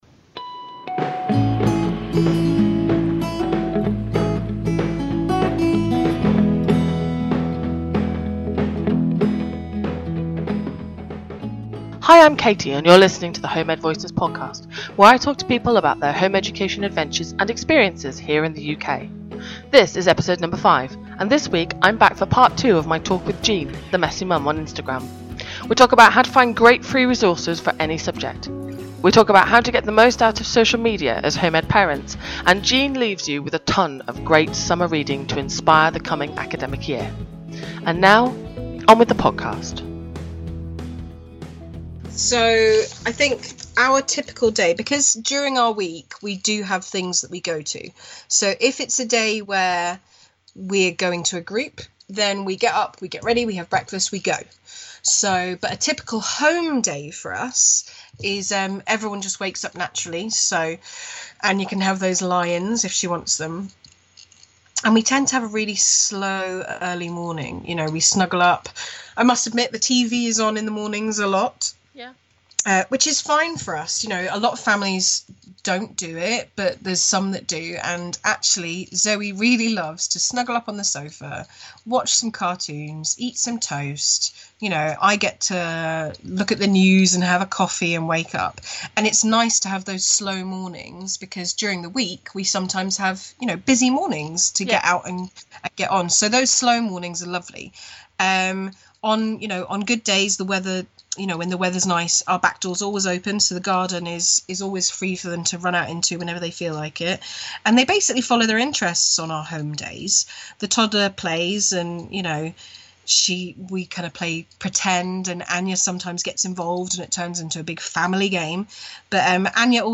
The Home Ed Voices Podcast is a UK-based Home Education Podcast that profiles the lives of home ed families.